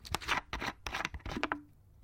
Звуки пластиковой крышки
Звук откручивания крышки пластиковой бутылки